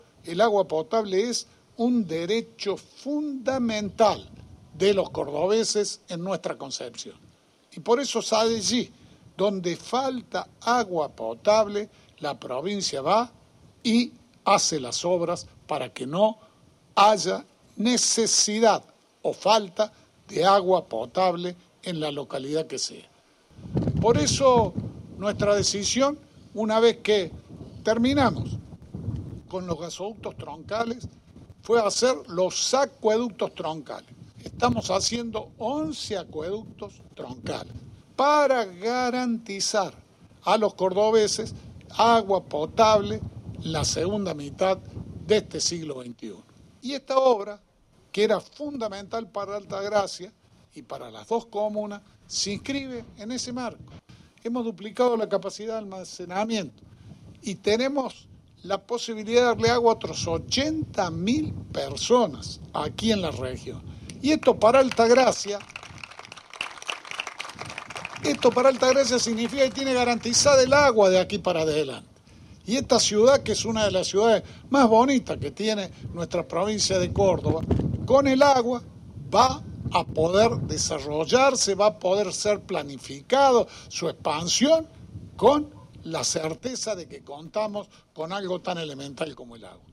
Audio: gobernador Schiaretti.
audio-gobernador-alta-gracia.mp3